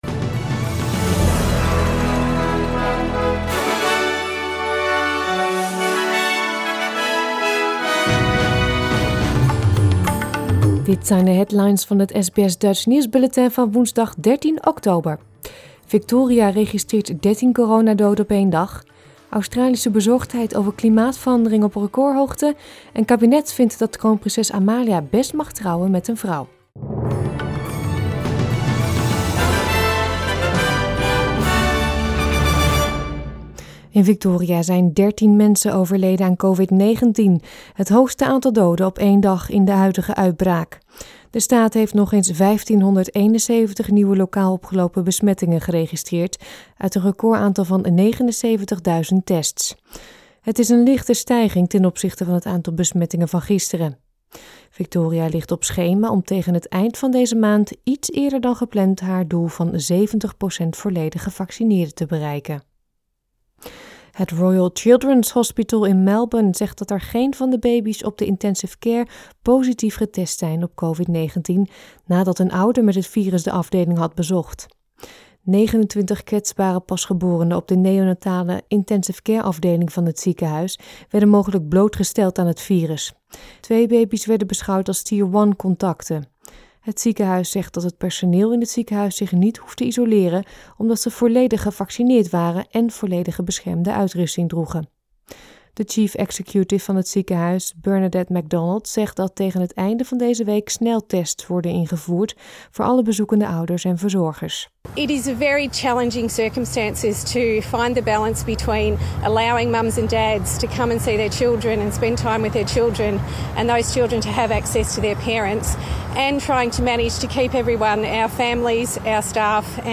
Nederlands/Australisch SBS Dutch nieuwsbulletin van woensdag 13 oktober 2021